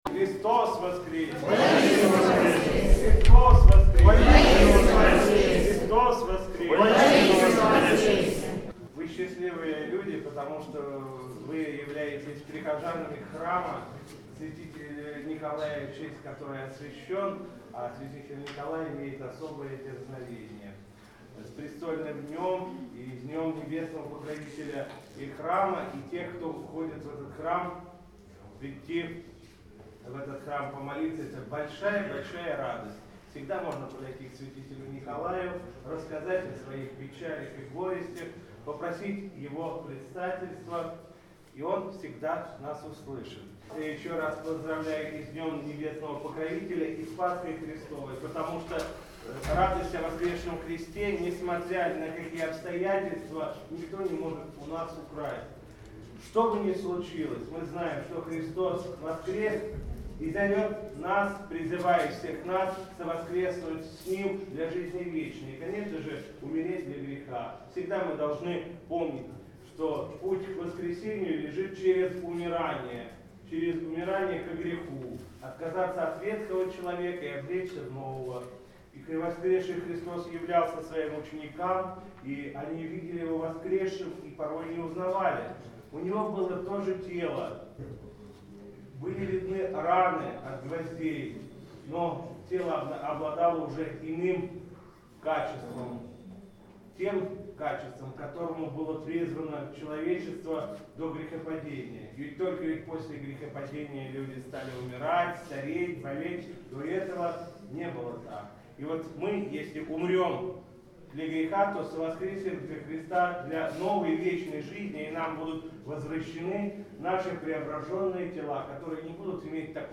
По окончании богослужения владыка обратился к прихожанам с архипастырским словом.
Словно на всенощном бдении в храме Святителя Николая во Владычной слободе